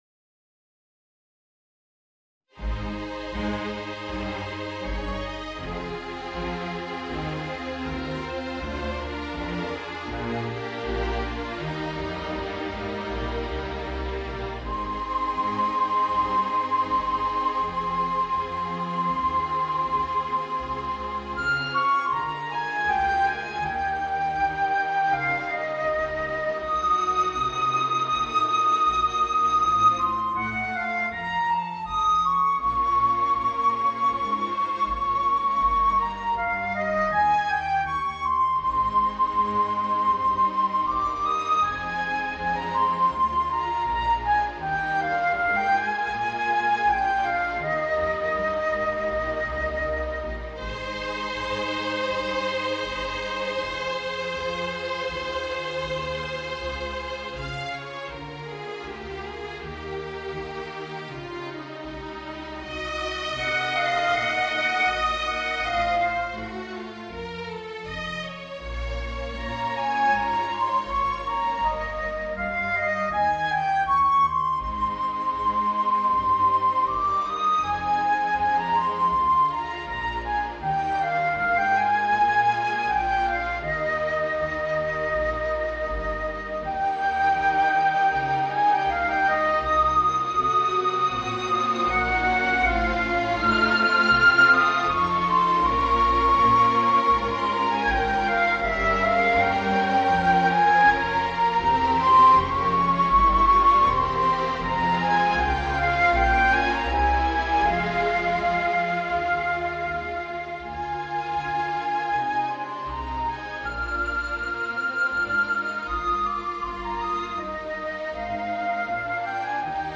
Voicing: Flute and String Orchestra